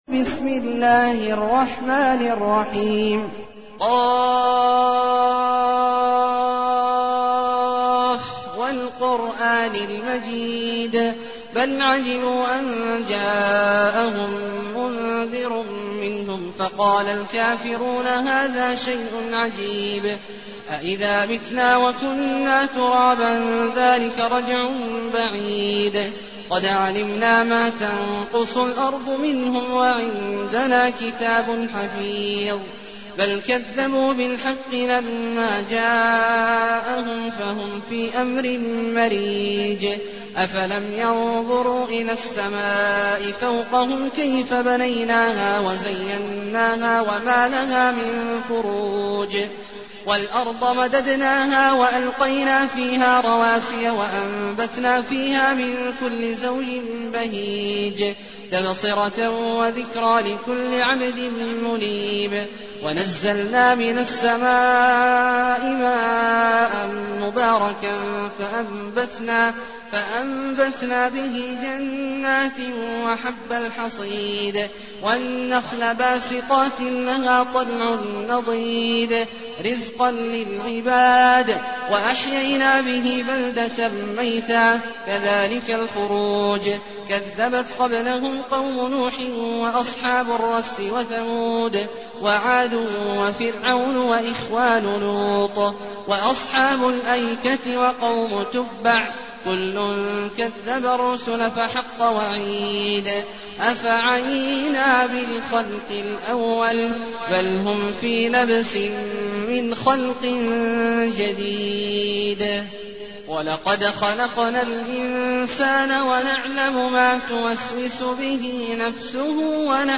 المكان: المسجد النبوي الشيخ: فضيلة الشيخ عبدالله الجهني فضيلة الشيخ عبدالله الجهني ق